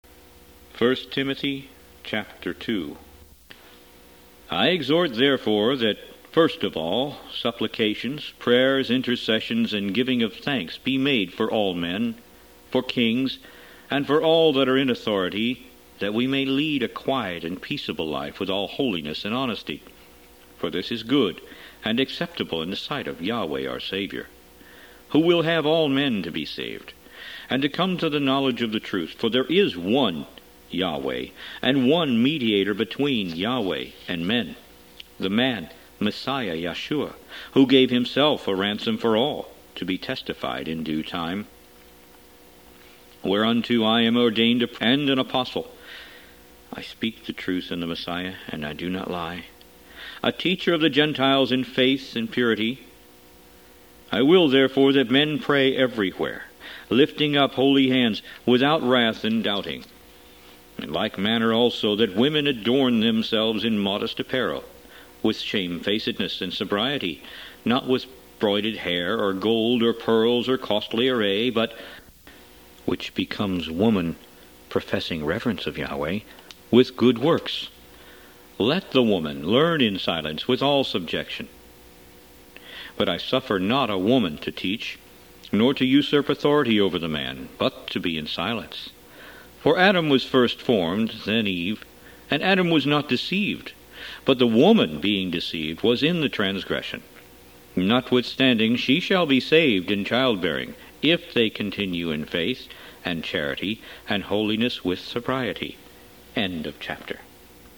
Root > BOOKS > Biblical (Books) > Audio Bibles > Messianic Bible - Audiobook > 15 The Book Of 1st Timothy